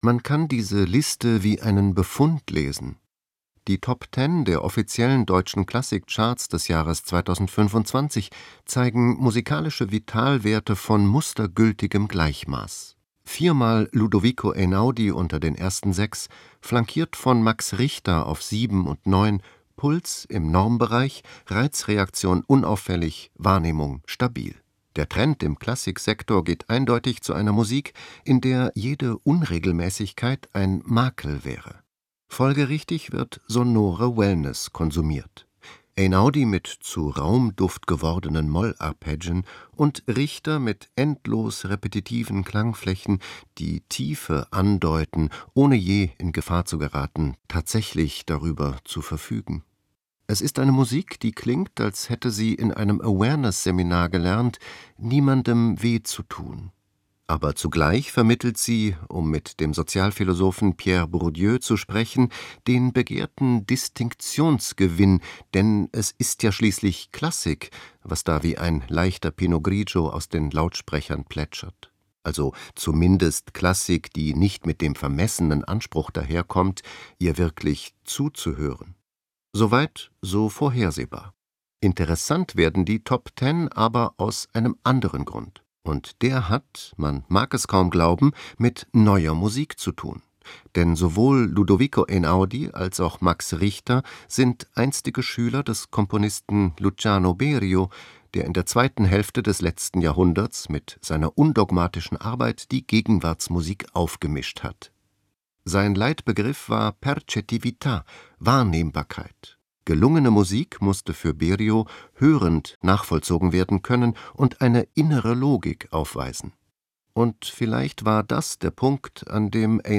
Glosse